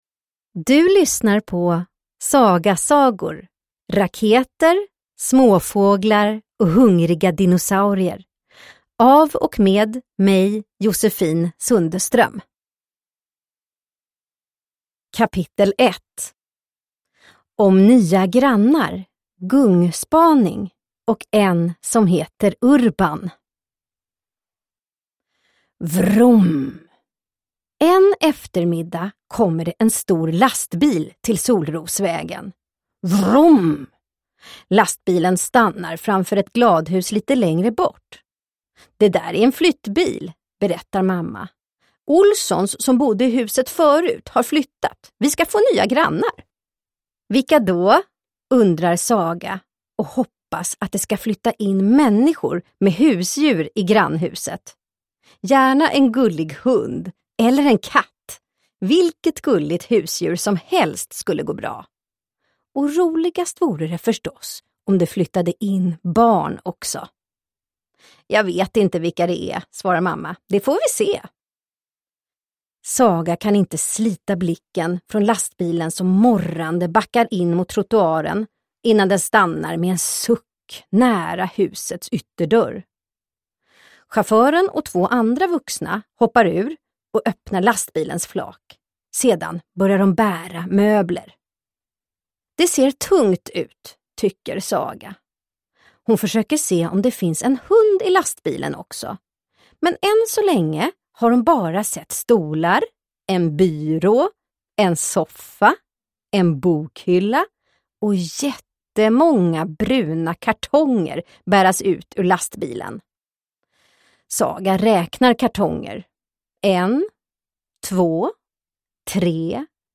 Raketer, småfåglar och hungriga dinosaurier – Ljudbok – Laddas ner